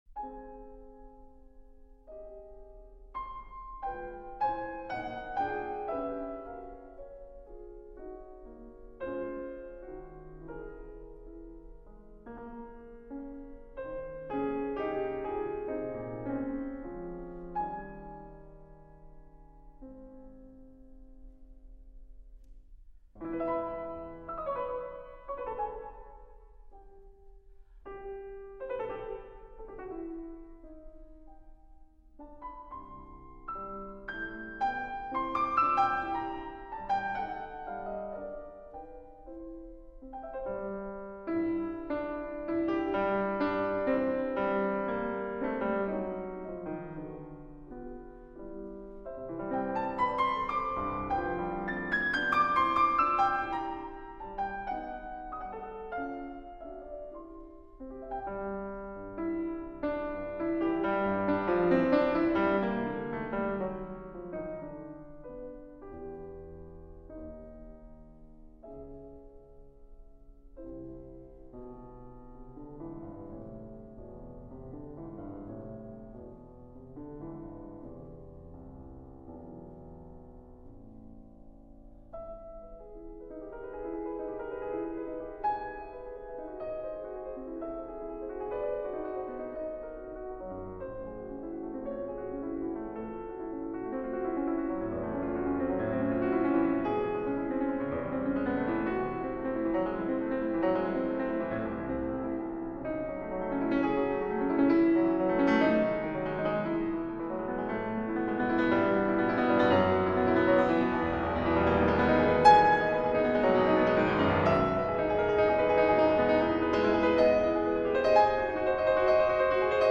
short piano movement
piano arrangements